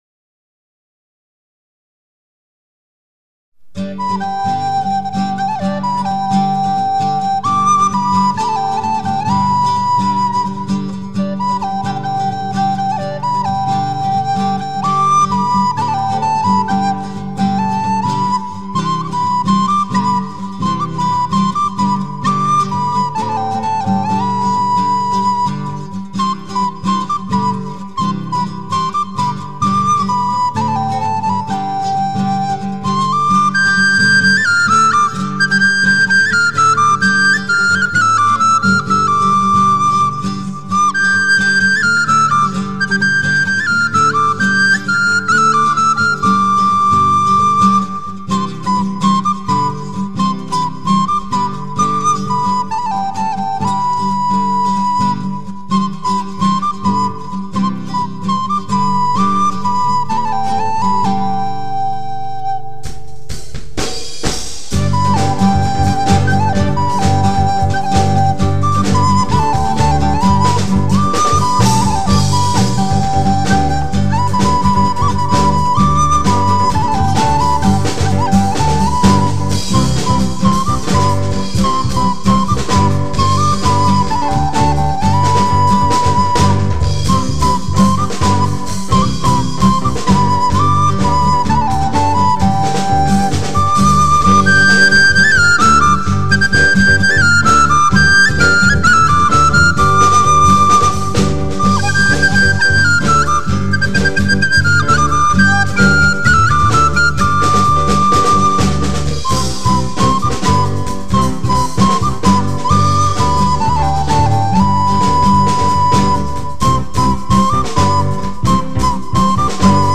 בביצוע מקפיץ משהו..
גיטרות, תופים, קלידים חלילית:  אני.
אהבתי איך באמצע החליפו לאורגנית..